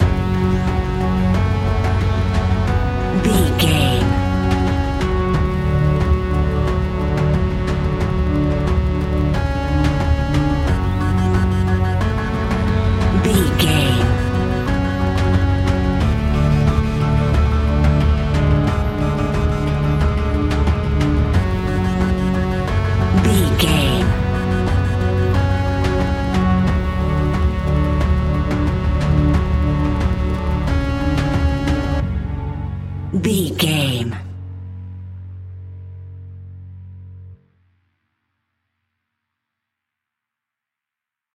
Aeolian/Minor
F#
scary
ominous
dark
eerie
industrial
drums
synthesiser
horror music